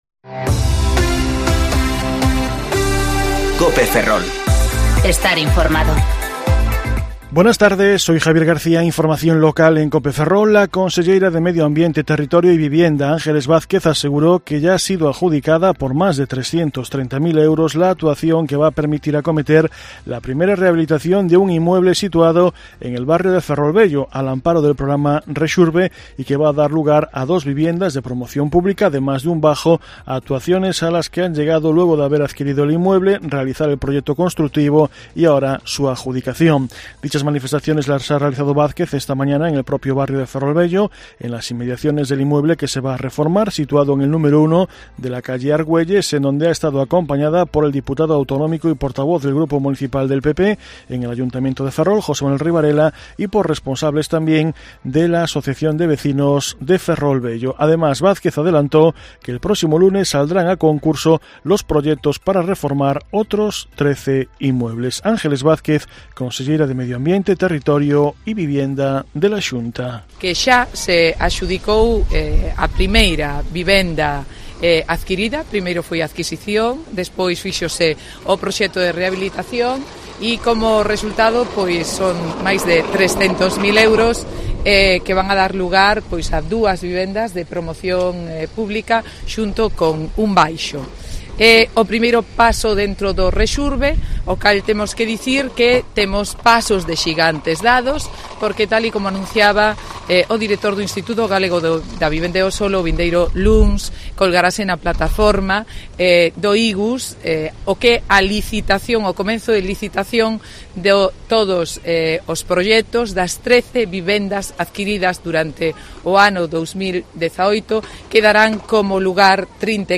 Informativo Mediodía Cope Ferrol 6/9/2019 (De 14.20 a 14.30 horas)